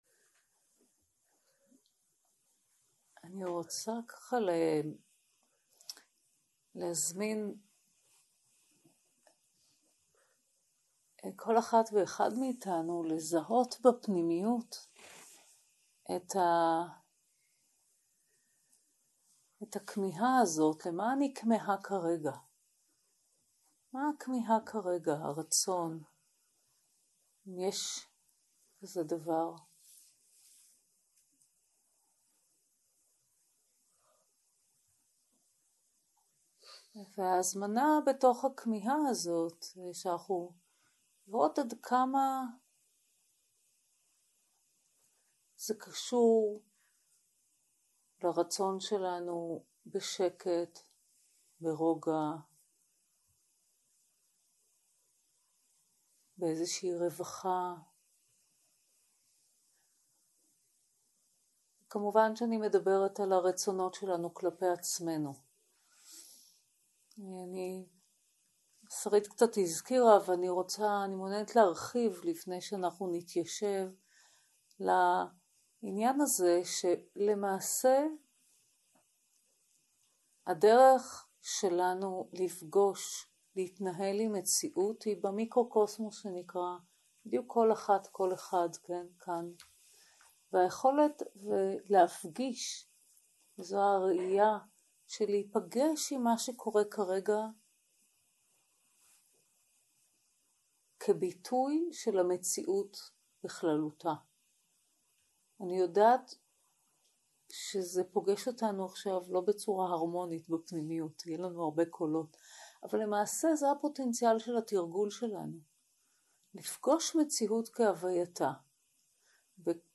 יום 1 - הקלטה 1 - ערב - שיחה ומדיטציה מונחית
סוג ההקלטה: שיחות דהרמה